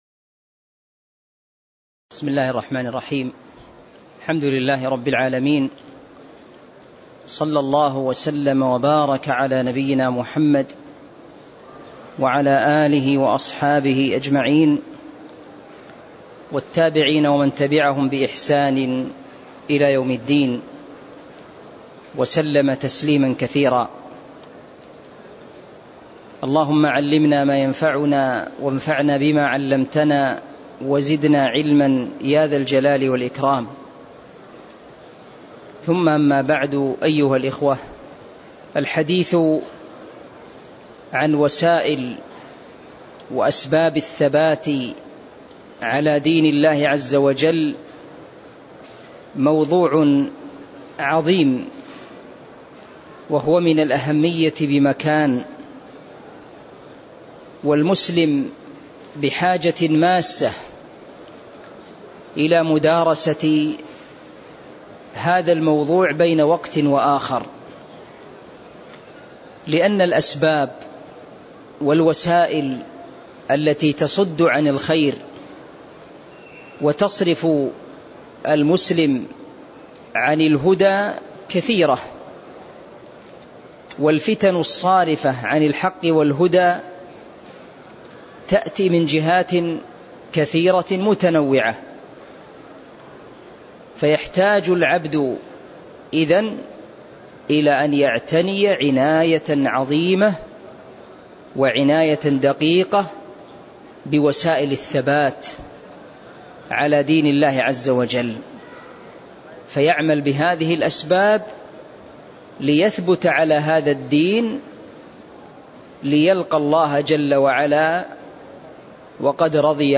تاريخ النشر ١٦ محرم ١٤٤٤ هـ المكان: المسجد النبوي الشيخ